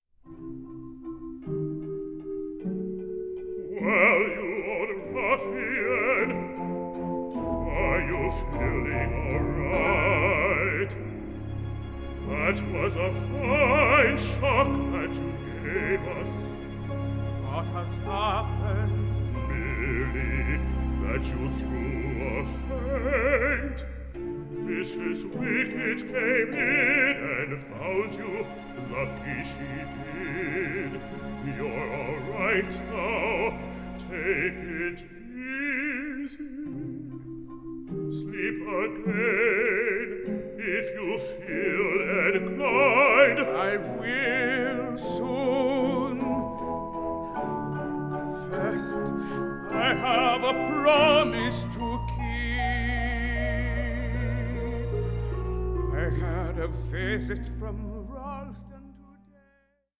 A FEEL-GOOD OPERA ABOUTTHE TEACHER WE ALL WISH WED HAD
new studio recording